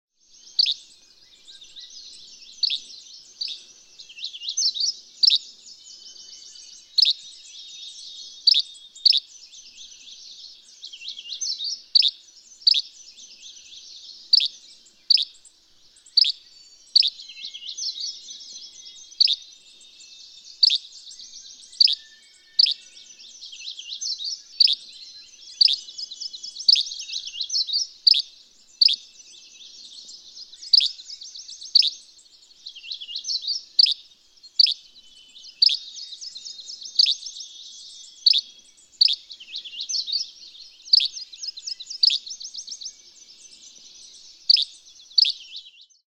Yellow-bellied flycatcher.
Sydney Lake, Ear Falls, Ontario.
630_Yellow-bellied_Flycatcher.mp3